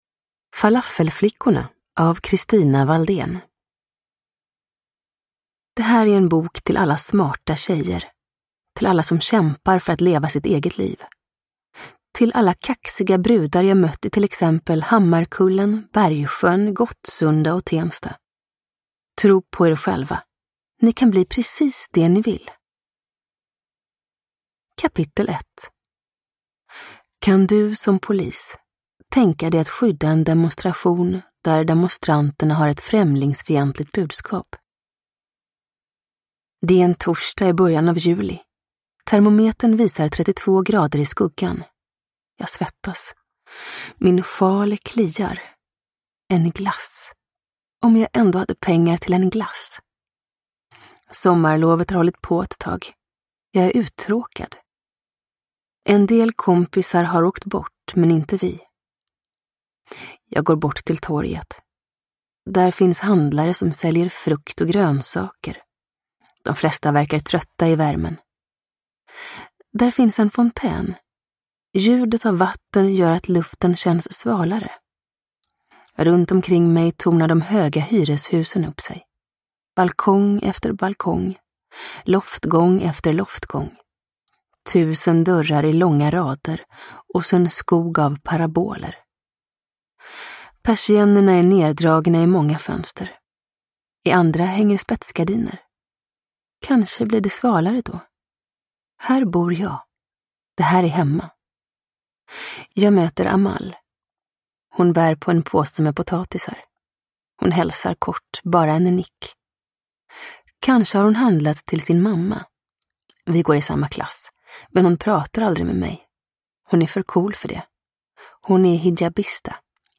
Falafelflickorna – Ljudbok – Laddas ner
Uppläsare: Julia Dufvenius